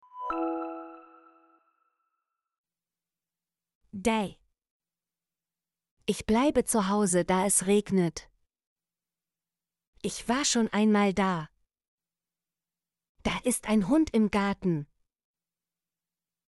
da - Example Sentences & Pronunciation, German Frequency List